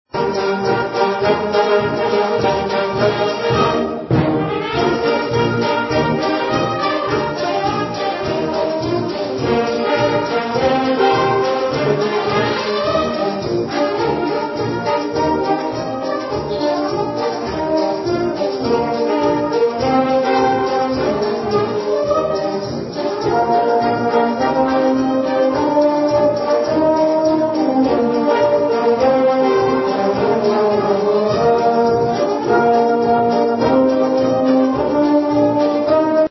The Emporia Granada Theatre’s 2022 concert season concluded with a festive holiday performance Sunday.
The Emporia Municipal Band presented its annual Christmas concert to the Emporia community. The afternoon’s selections included different festive arrangements from around the world as well as some of the holiday classics including Jingle Bells, Sleigh Ride and Here Comes Santa Claus just to name a few.